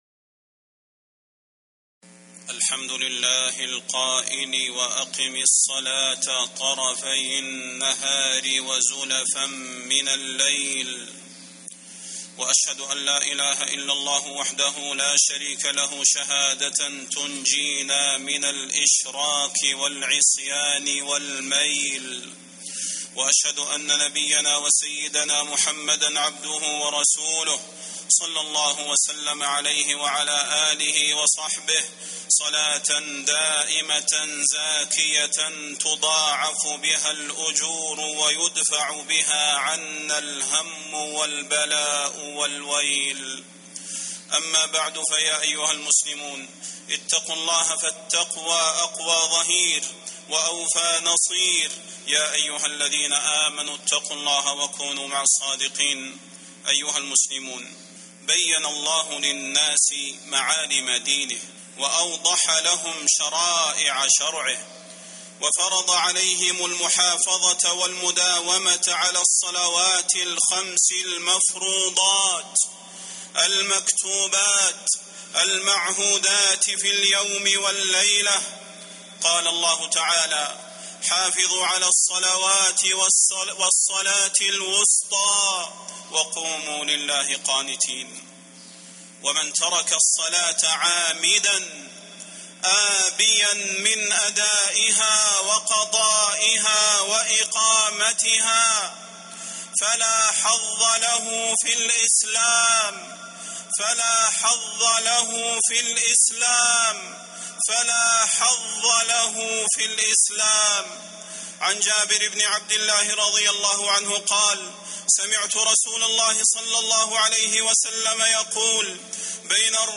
فضيلة الشيخ د. صلاح بن محمد البدير
تاريخ النشر ٨ رجب ١٤٤٠ هـ المكان: المسجد النبوي الشيخ: فضيلة الشيخ د. صلاح بن محمد البدير فضيلة الشيخ د. صلاح بن محمد البدير المحافظة على الصلوات الخمس المفروضات